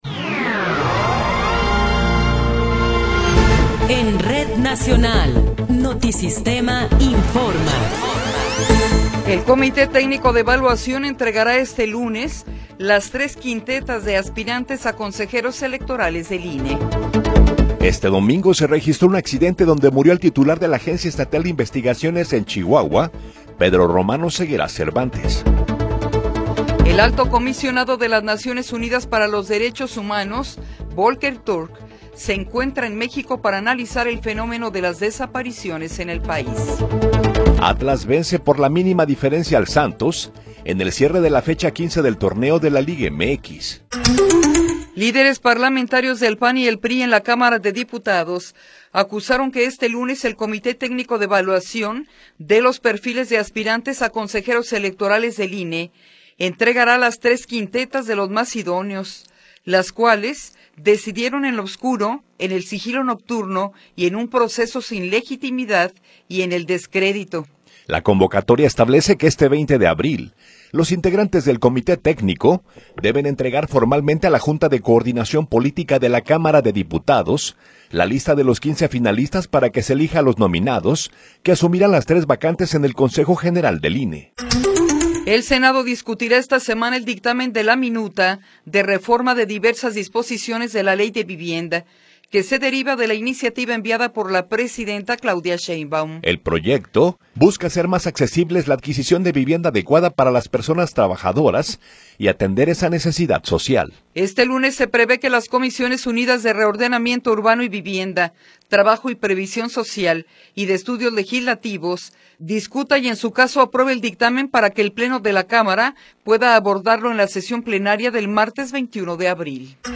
Noticiero 8 hrs. – 20 de Abril de 2026
Resumen informativo Notisistema, la mejor y más completa información cada hora en la hora.